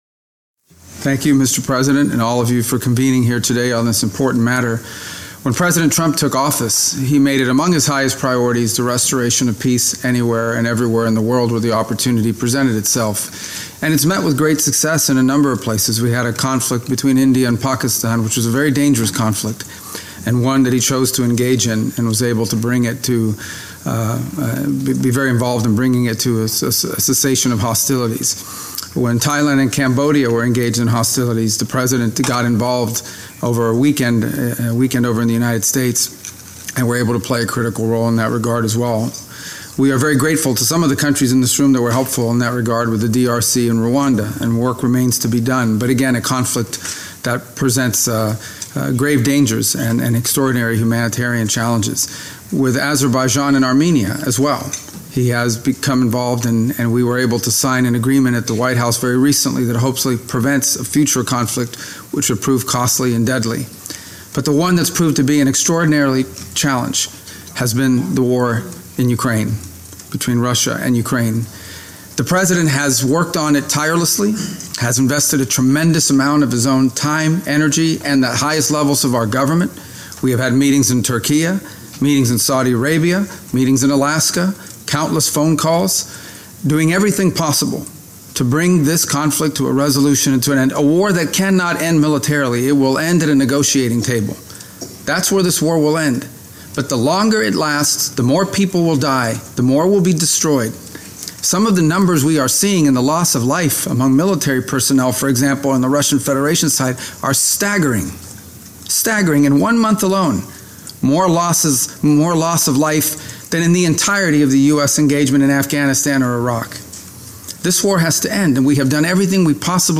Remarks to a United Nations Security Council Ministerial Meeting on Ukraine
delivered 23 September 2025, UN Headquarters, New York, NY
Audio Note: AR-XE = American Rhetoric Extreme Enhancement